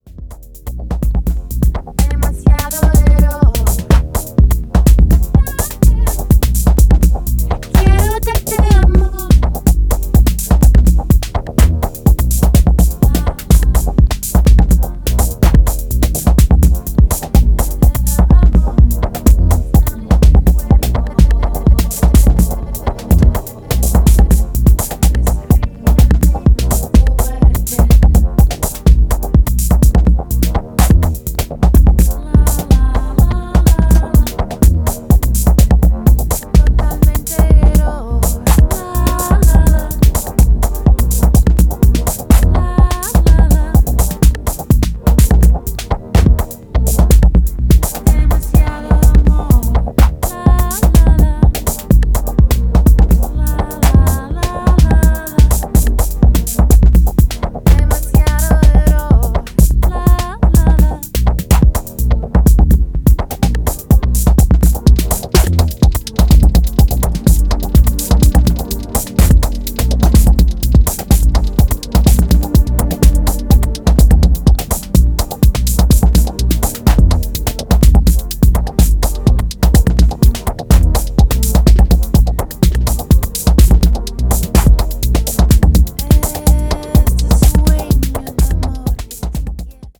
後半に連れて施されていくトリッピーな音響工作にも、じっくり引き込まれていきますね！